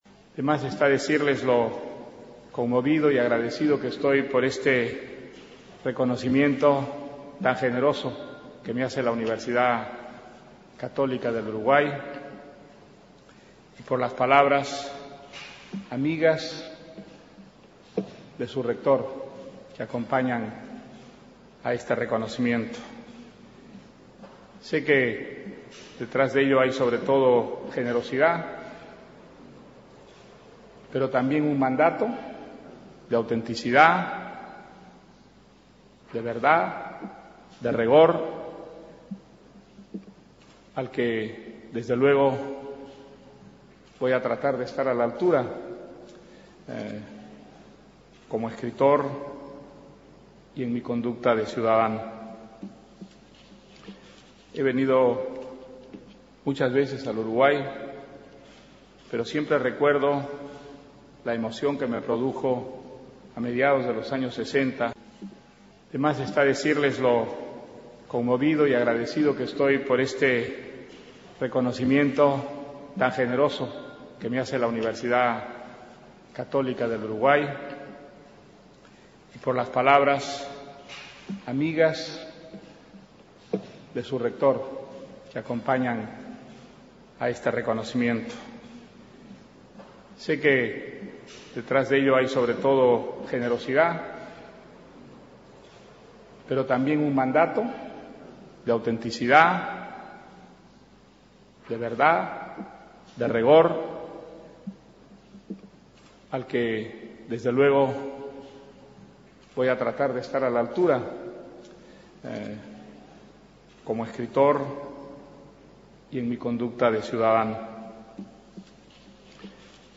Conferencia de Mario Vargas Llosa en la Universidad Católica del Uruguay
El Premio Nobel de Literatura 2010, Mario Vargas Llosa, fue homenajeado el jueves por la Universidad Católica del Uruguay, donde recibió la Gran Medalla Académica de esa institución y pronunció la "Lectio inauguralis" de la actividad 2011.